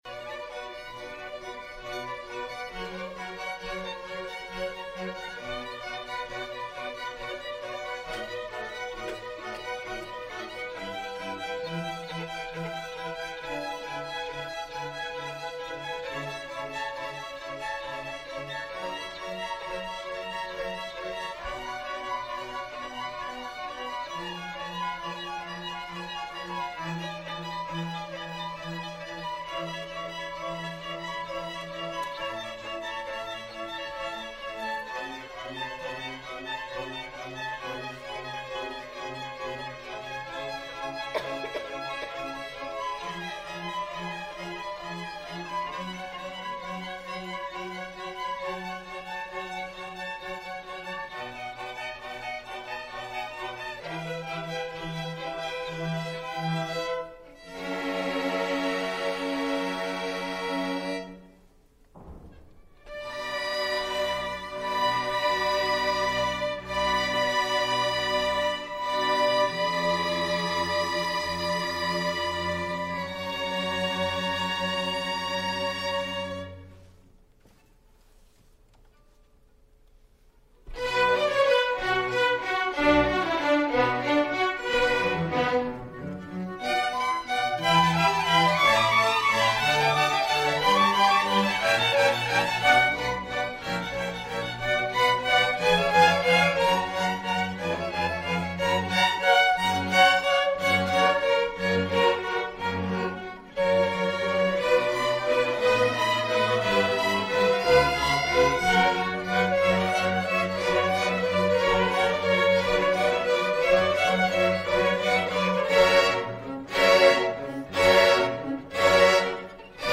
Queen City Community Orchestra
Fall 2018 Concert
Concerto for 4 Violins